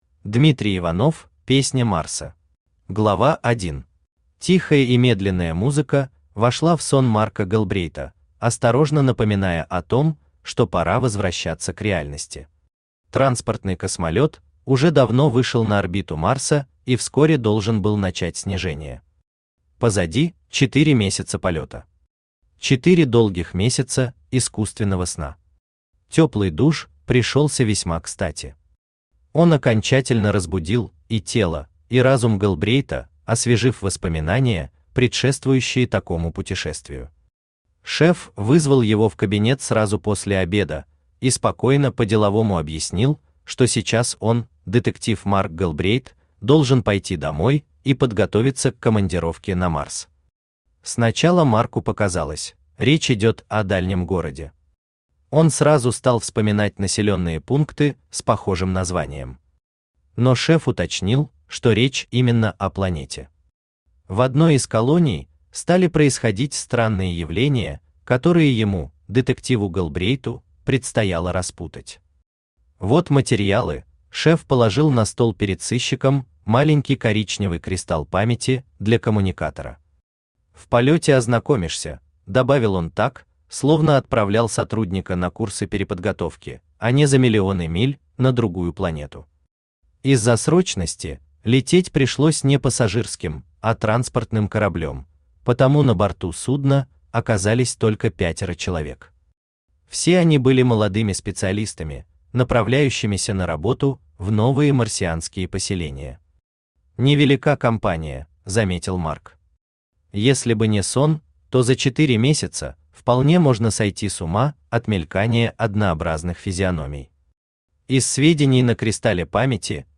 Aудиокнига Песня Марса Автор Дмитрий Владимирович Иванов Читает аудиокнигу Авточтец ЛитРес.